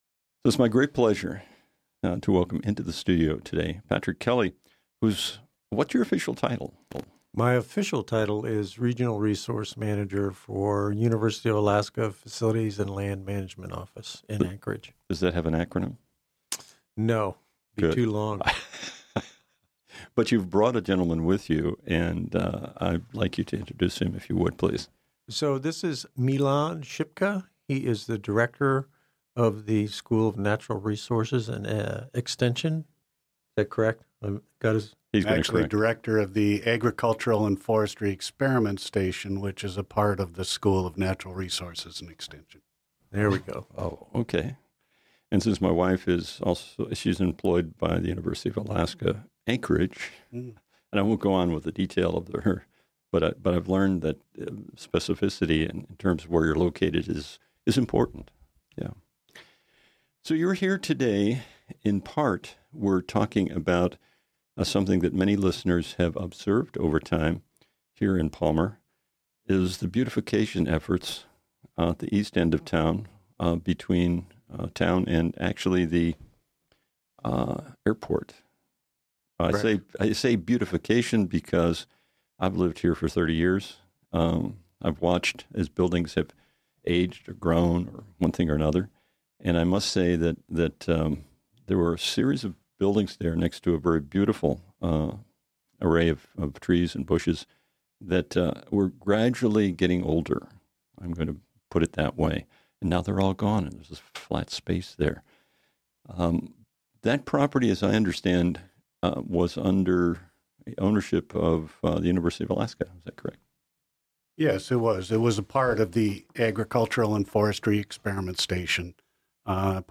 Discussions and interviews with employees and administrators from the city of Palmer